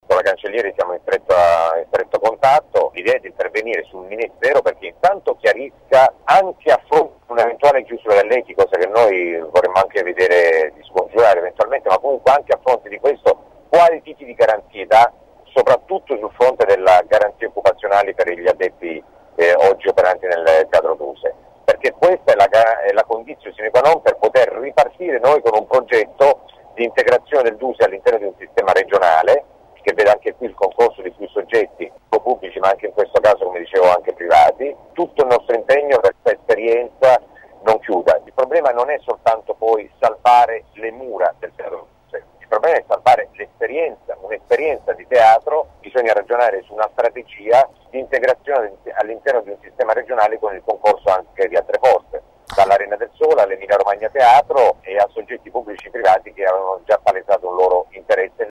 Sono le parole dell’assessore regionale alla cultura Massimo Mezzetti intervenuto questa mattina durante Angolo B sulla questione del teatro Duse, il cui futuro è appeso ad un filo dopo la soppressione dell’Eti (ente de teatro italiano) decretata dal governo nella manovra finanziaria.
Ascolta l’assessore Mezzetti